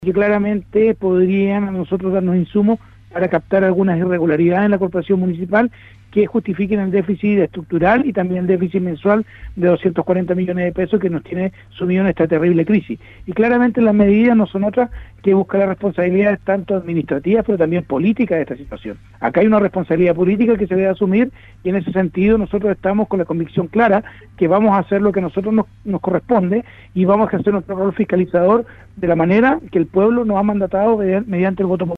El concejal Andrés Ibáñez, Presidente de la Comisión de Educación del concejo municipal de Ancud, indicó que solicitaron una auditoria para conocer cuáles son las implicancias contables de los incumplimientos de la Corporación y determinar además las responsabilidades políticas.